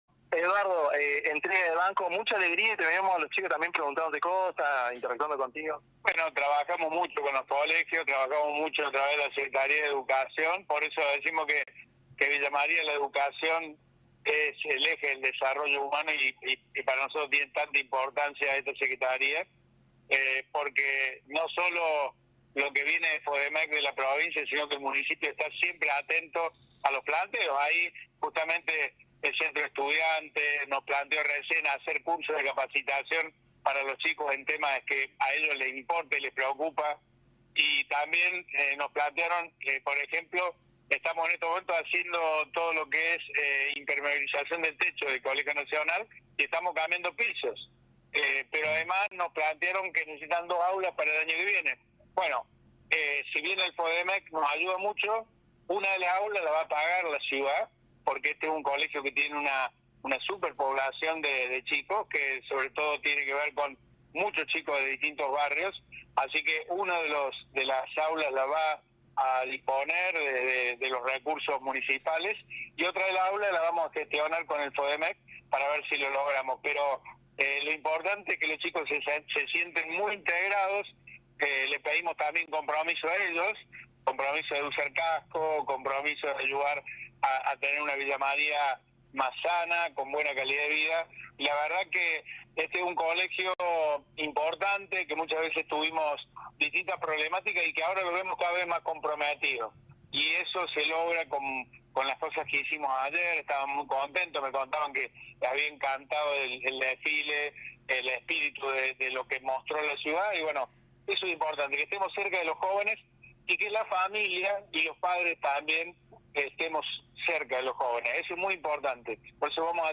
Entrevista a Eduardo Accastello, intendente de Villa María